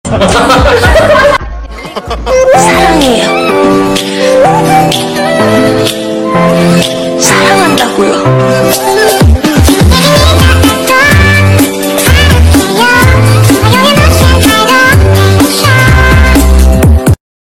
crush 's laugh is the sound effects free download
crush 's laugh is the sweetest sound